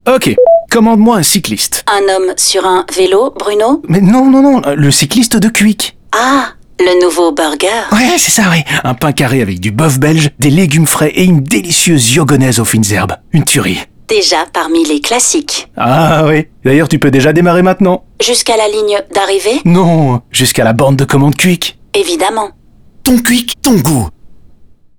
Côté coaching, Happiness a mouillé son maillot en créant le concept du burger, des spots TV et audio dans les restaurants, du (D)OOH en en 2, 8, 10 et 20 m2, ainsi que du matériel point de vente et une campagne sur les réseaux sociaux.
Sound production